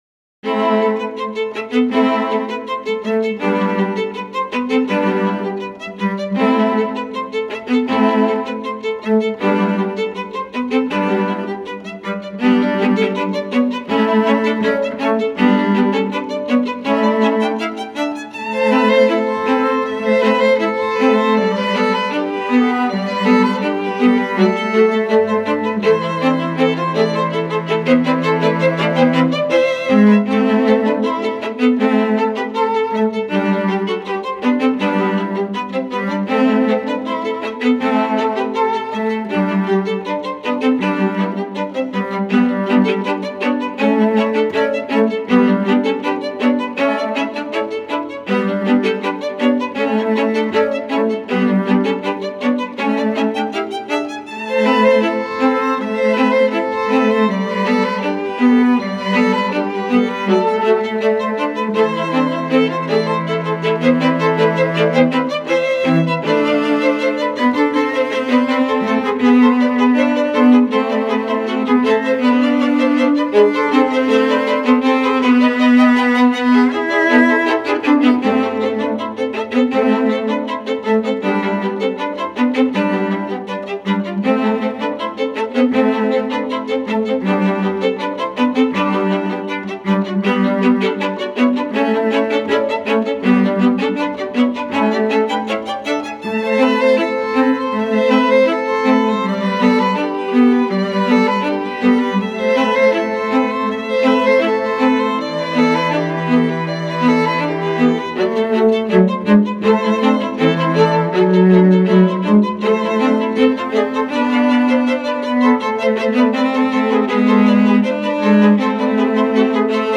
广告片背景音乐